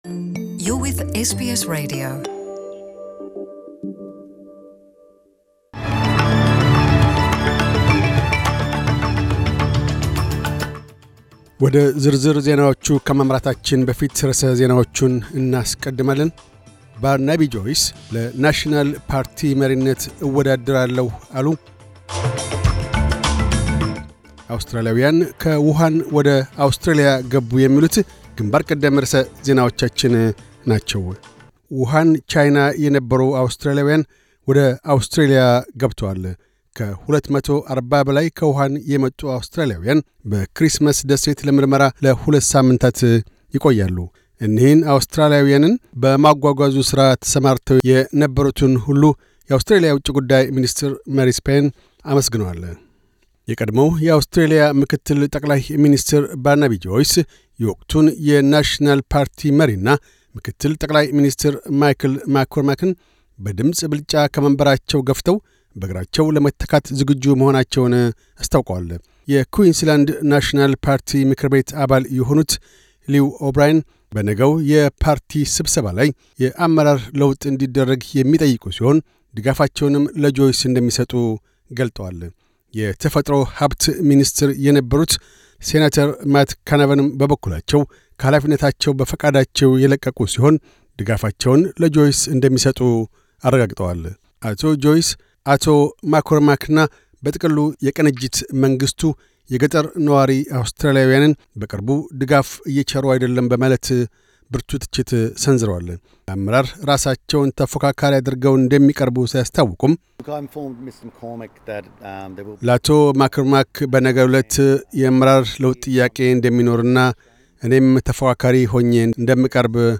News Bulletin 0302